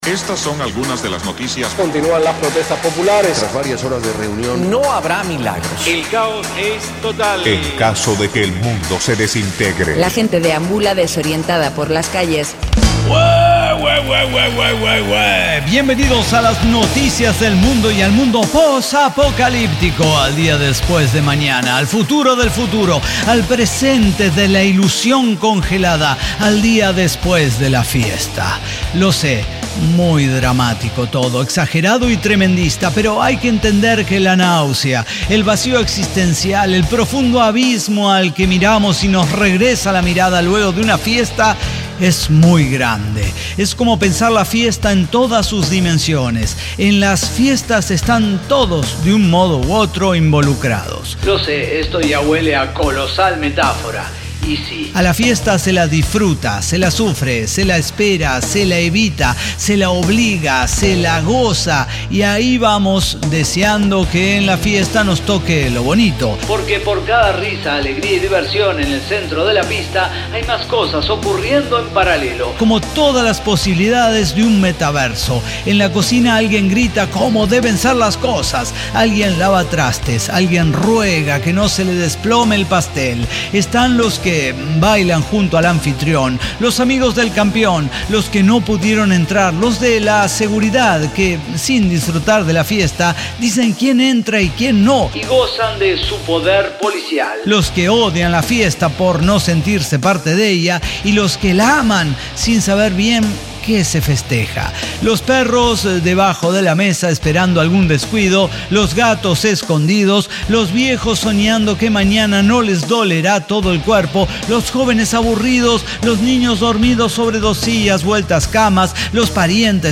ECDQEMSD podcast El Cyber Talk Show – episodio 5771 Después De La Fiesta